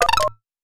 SCFI_button_5.wav